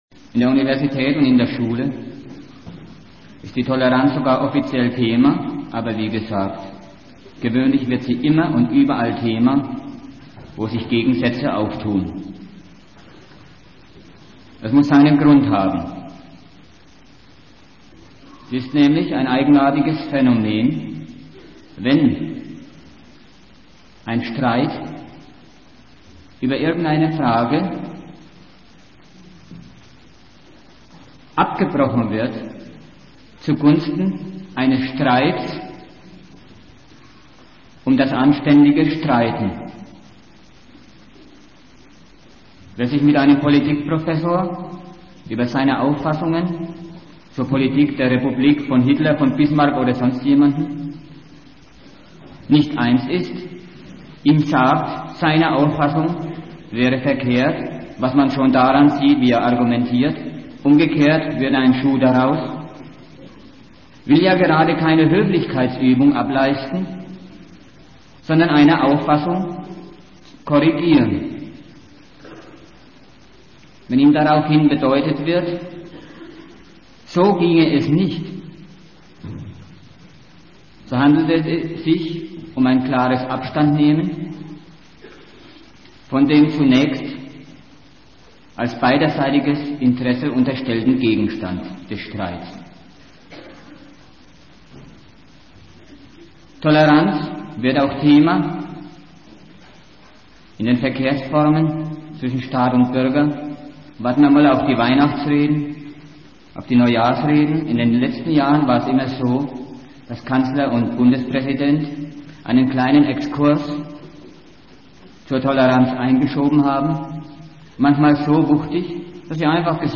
Gliederung des Vortrages